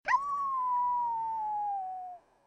awoooo.mp3